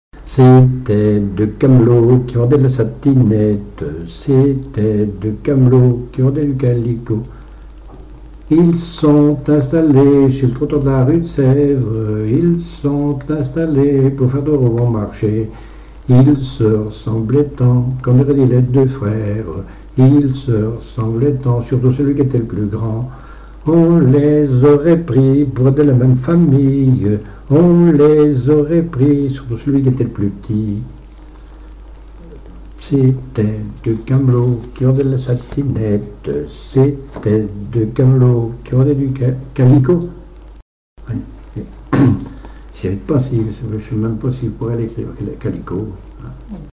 Chants brefs - A danser
danse : polka piquée
Pièce musicale inédite